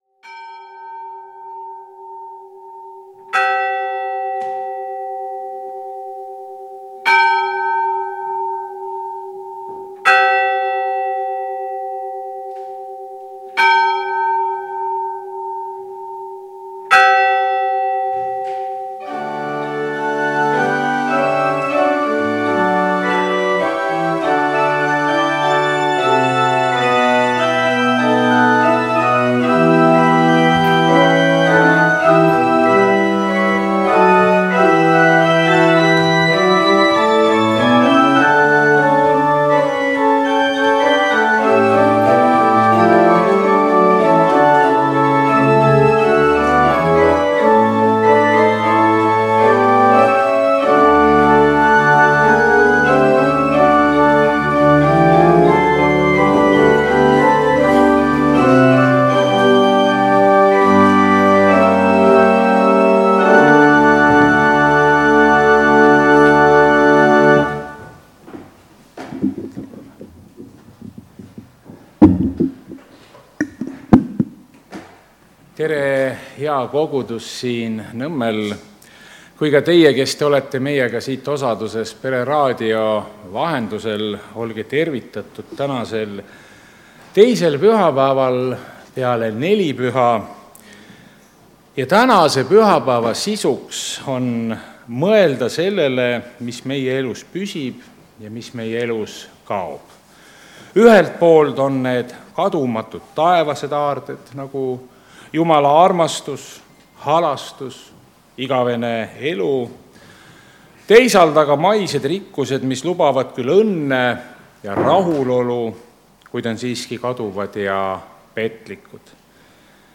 Jumalateenistus 22. juuni 2025